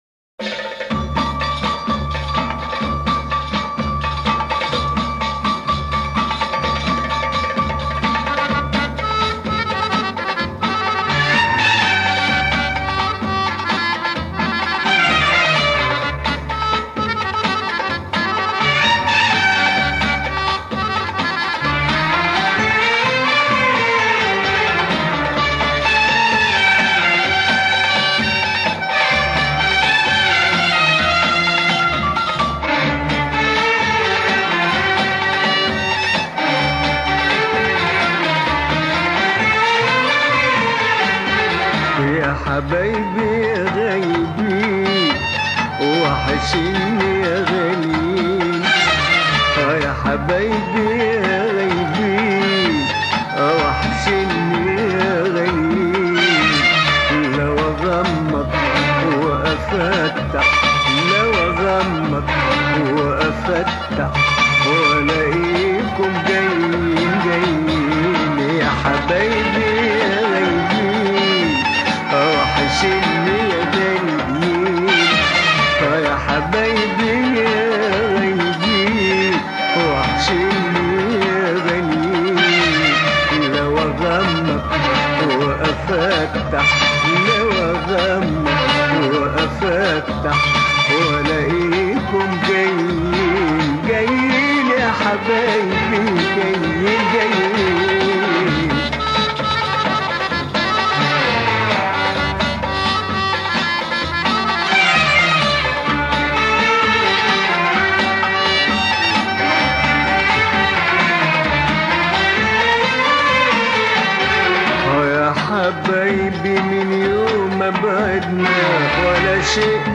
Famoso cantor, compositor, alaúdista e ator sírio-egípcio.